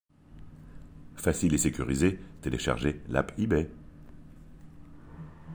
- Basse